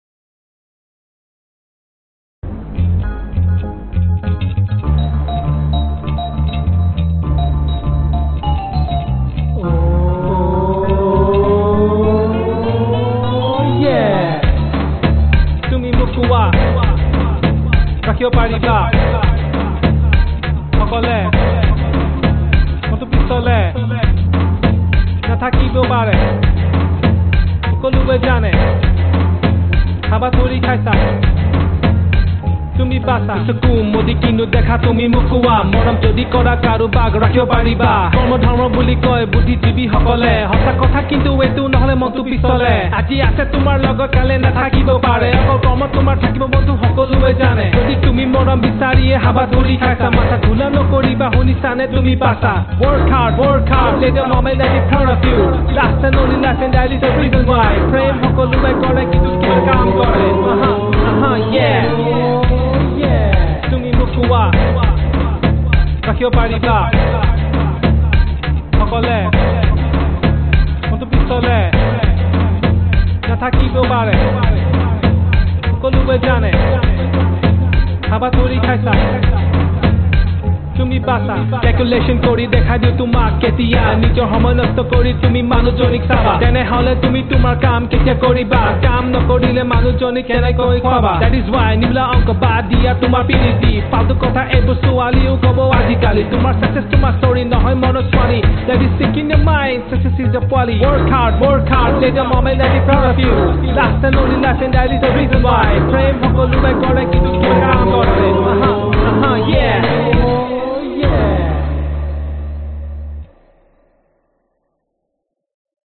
风 - 声音 - 淘声网 - 免费音效素材资源|视频游戏配乐下载
首先是手风琴上的麦克风，没有声音传出来。 然后当然是长笛。
但当开口足够大时，就会产生风的声音。
声道立体声